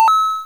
pickup1.wav